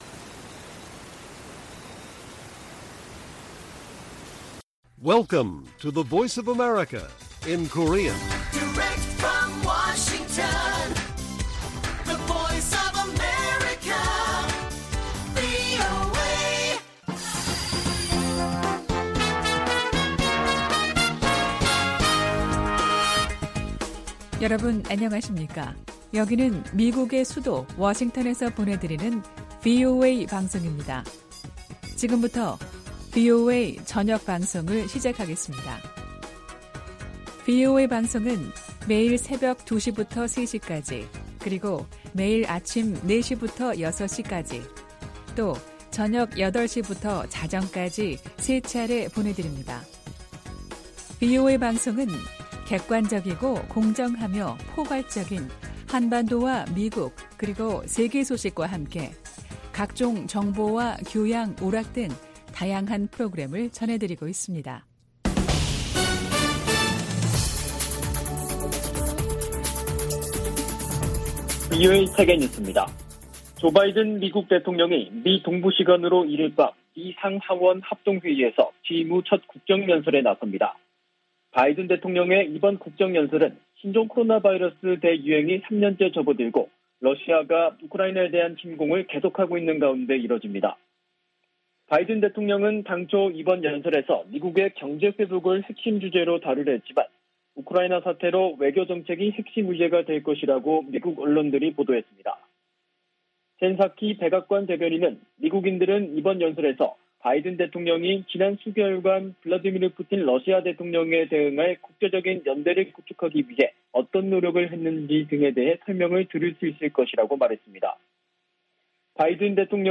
VOA 한국어 간판 뉴스 프로그램 '뉴스 투데이', 2022년 3월 1일 1부 방송입니다. 미국, 영국, 일본 등 유엔 안보리 11개국이 북한 탄도미사일 발사를 규탄하고 단호한 제재 이행 방침을 확인했습니다. 괌 당국은 북한의 탄도미사일 시험 발사 재개와 관련해 모든 움직임을 계속 감시하고 있다고 밝혔습니다. 미국의 전문가들은 북한의 최근 미사일 발사가 우주개발을 가장한 탄도미사일 시험일 뿐이라고 지적하고 있습니다.